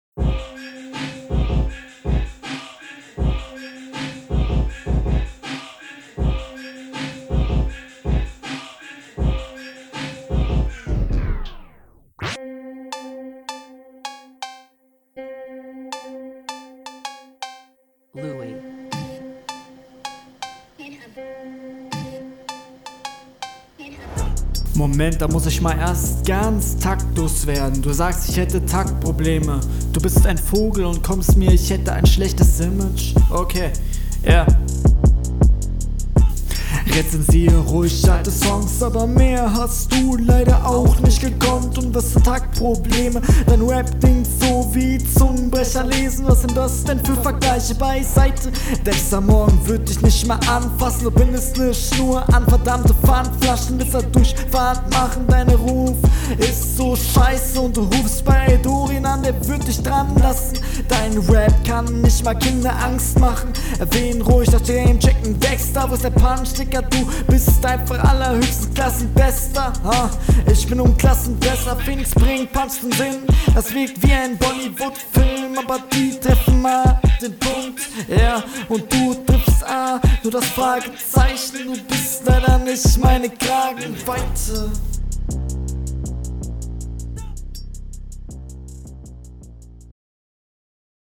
Intro komplett neben dem Takt.